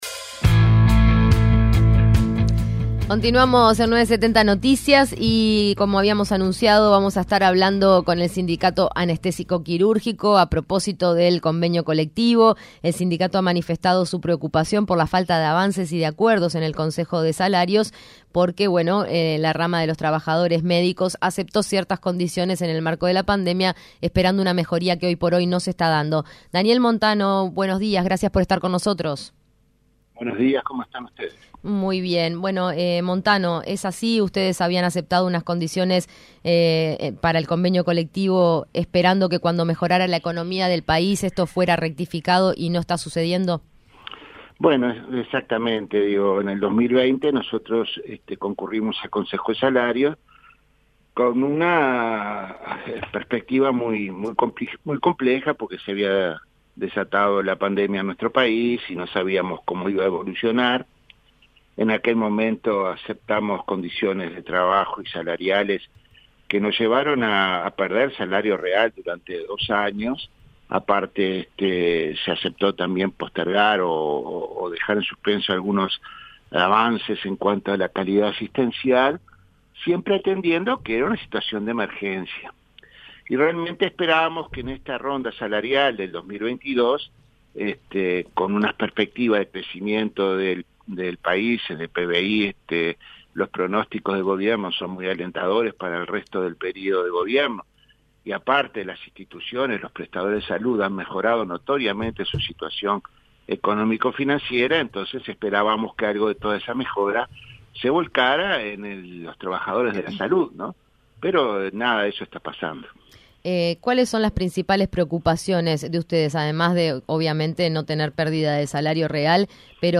fue entrevistado en 970 Noticias Primera Edición, en relación al convenio colectivo, la preocupación presentada desde el sindicato por la falta de avances en el Consejo de Salarios y la pérdida salarial que han sufrido durante la pandemia.